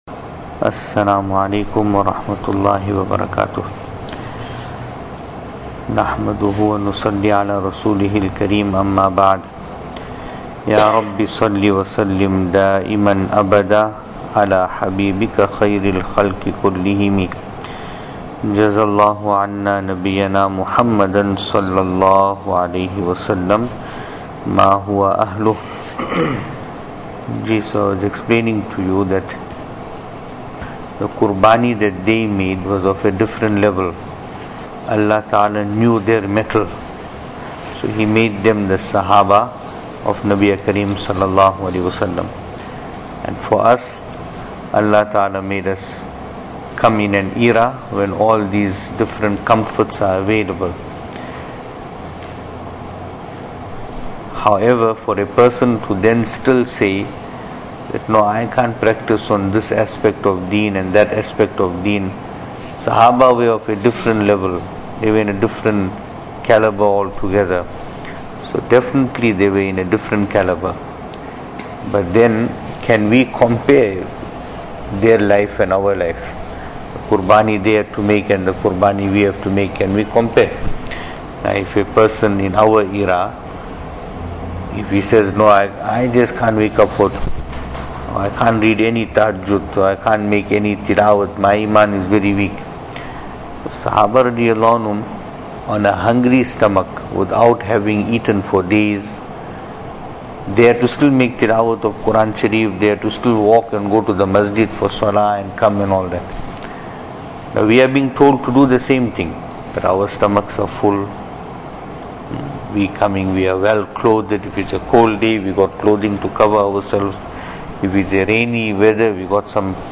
Venue: Masjid Taqwa, Pietermaritzburg | Series: Seerah Of Nabi (S.A.W)
Service Type: Majlis